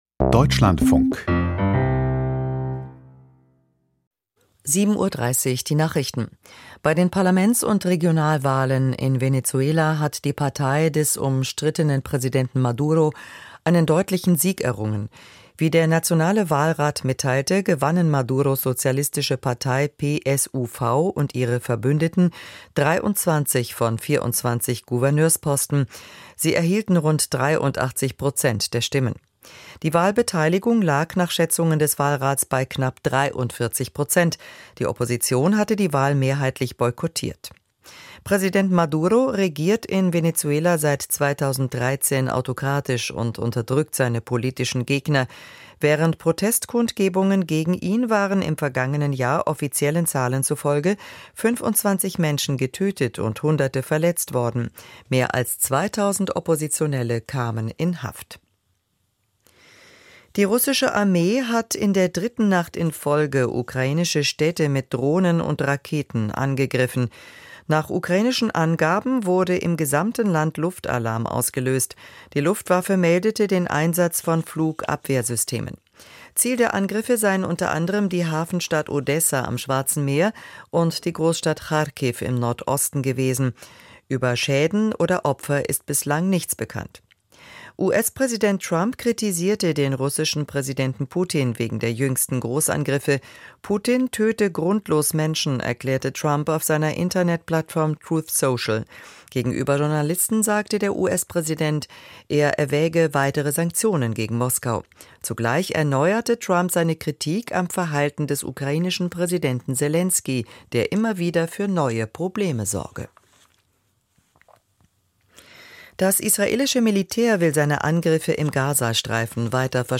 Ukraine-Hilfe: Interview mit Thomas Röwekamp, CDU, Vors. Verteidigungsausschuss - 26.05.2025